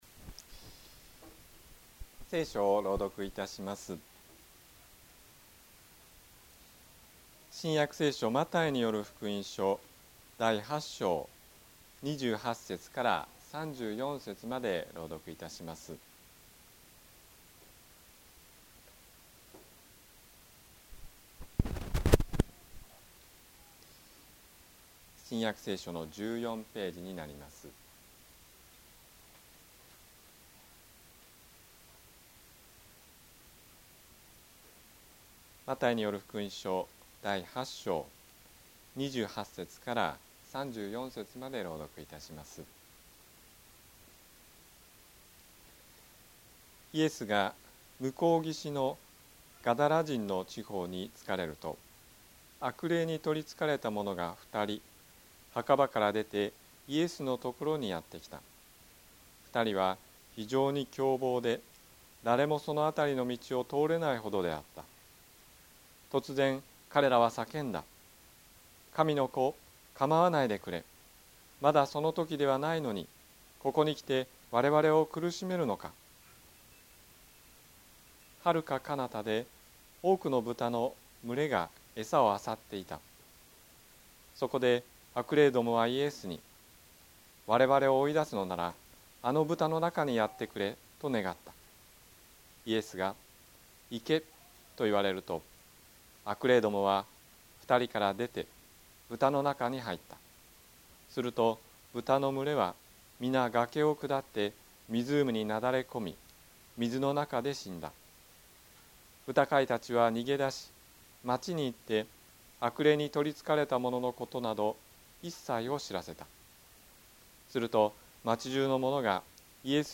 宝塚の教会。説教アーカイブ。
日曜 朝の礼拝